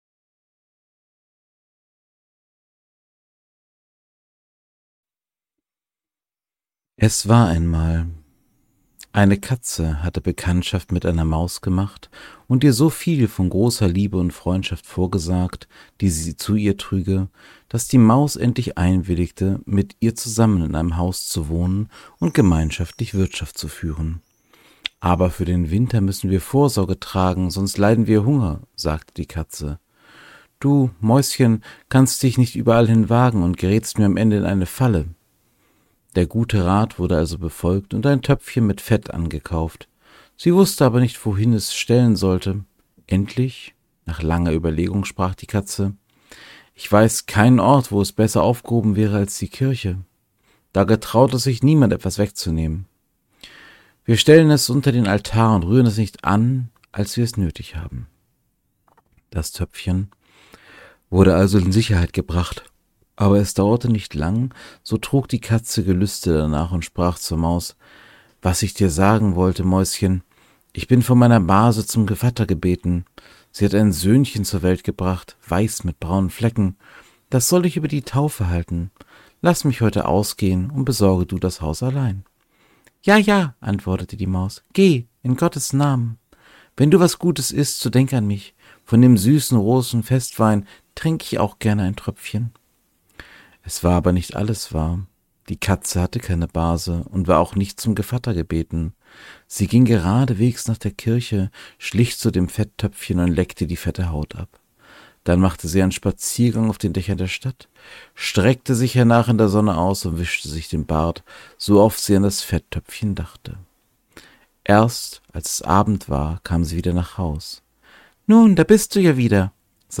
In diesem kleinen Podcast Projekt lese ich Märchen vor.